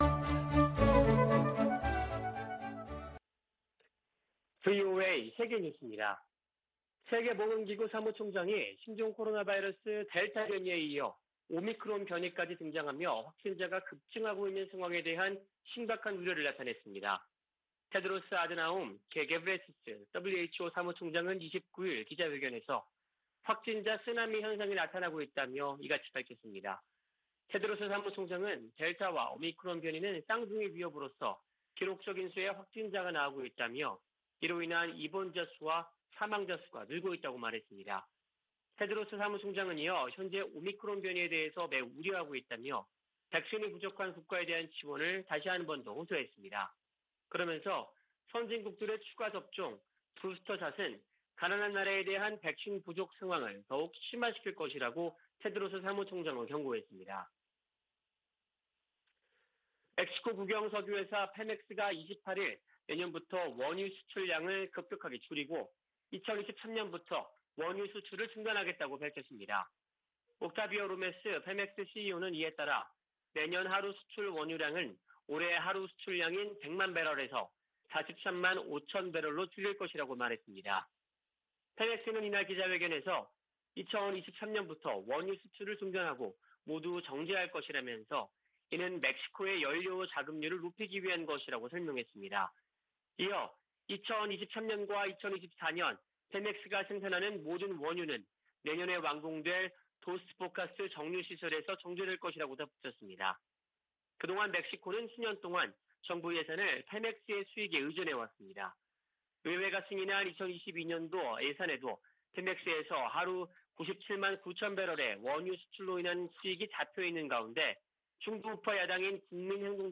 VOA 한국어 아침 뉴스 프로그램 '워싱턴 뉴스 광장' 2021년 12월 30일 방송입니다. 미 국무부는 한반도의 항구적 평화 달성을 위해 북한과의 외교에 전념하고 있다고 밝혔습니다. 정의용 한국 외교부 장관은 미-한 간 종전선언 문안 조율이 마무리됐다고 밝혔습니다. 조 바이든 미국 대통령이 역대 최대 규모 국방예산을 담은 2022국방수권법안에 서명했습니다.